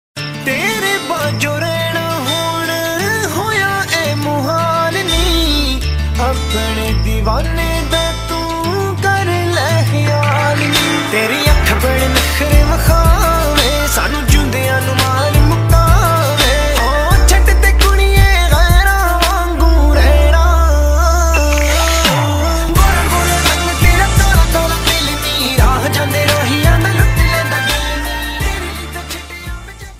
song Panjabi Category: panjabi song